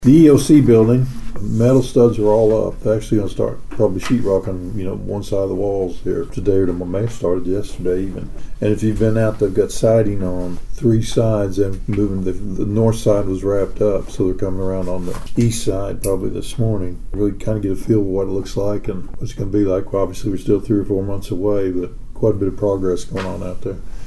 Commissioner Mike Dunlap gave an
Mike Dunlap on EOC Progress 9-3.mp3